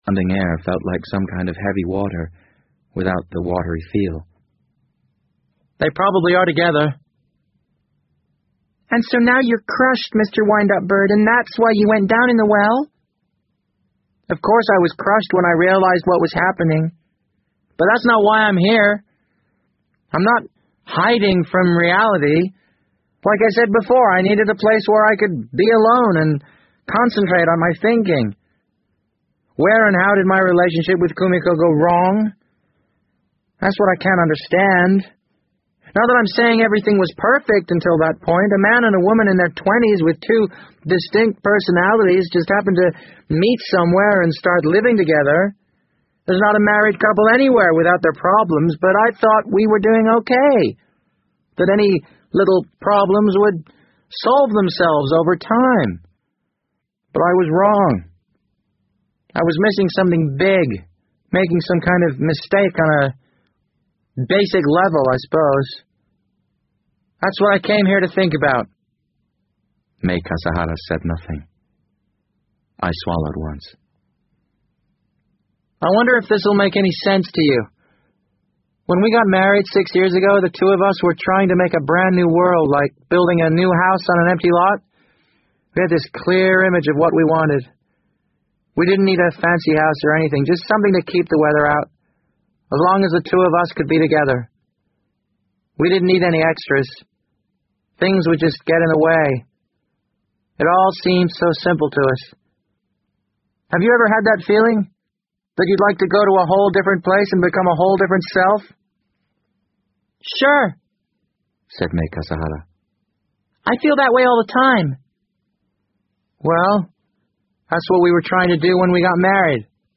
BBC英文广播剧在线听 The Wind Up Bird 007 - 8 听力文件下载—在线英语听力室